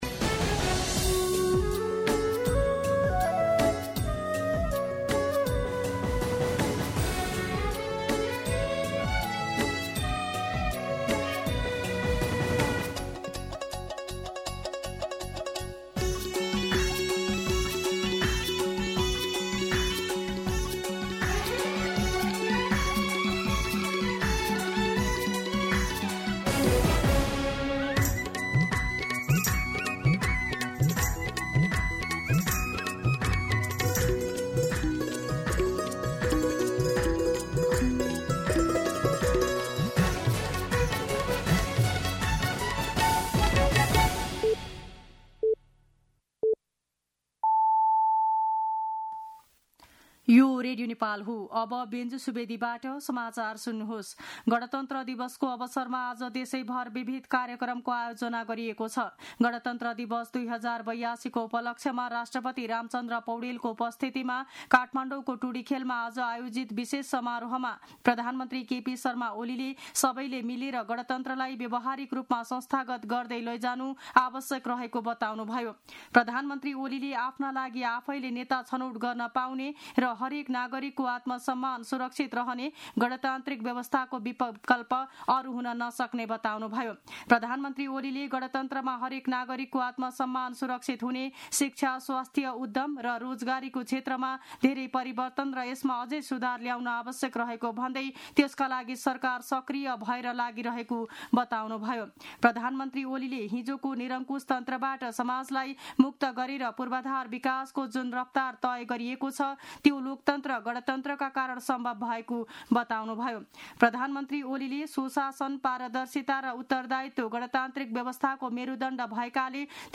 दिउँसो १ बजेको नेपाली समाचार : १५ जेठ , २०८२